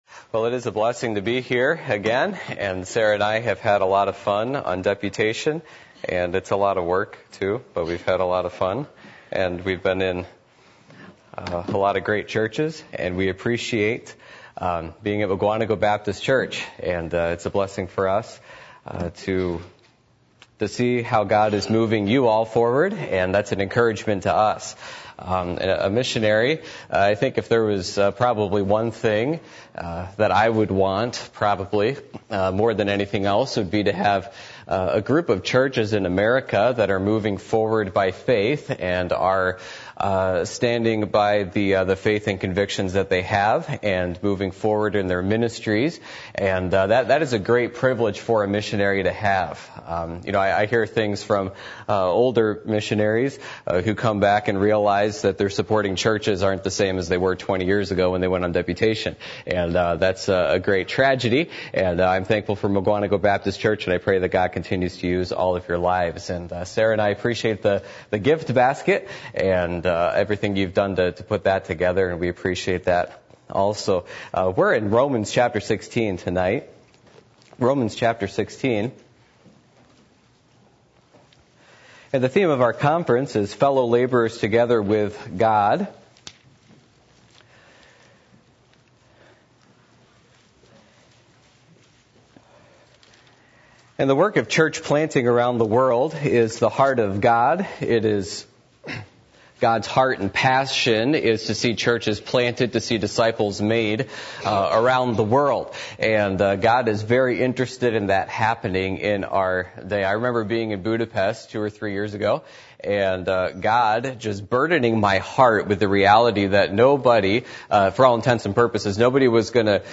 Passage: Acts 18:1-3 Service Type: Missions Conference